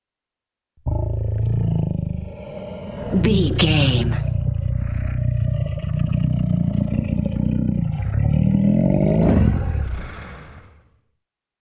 Monster growl snarl predator
Sound Effects
scary
ominous
eerie